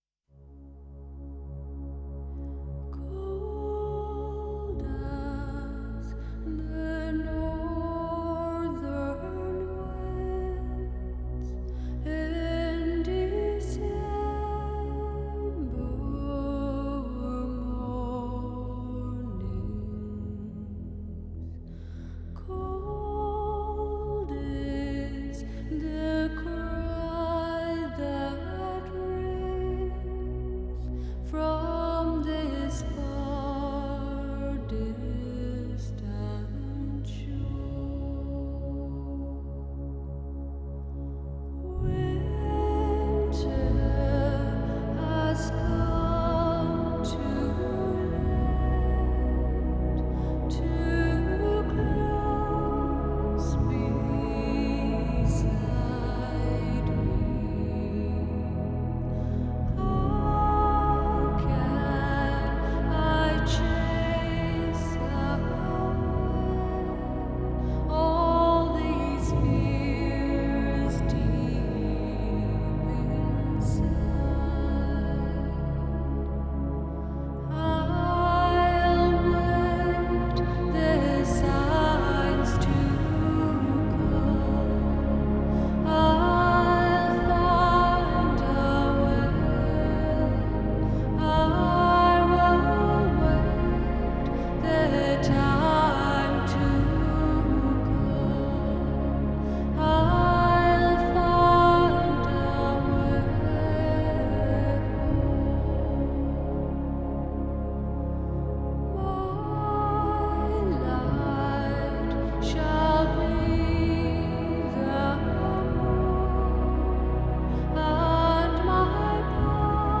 Genre: New Age
Recorded at Orinoco Studios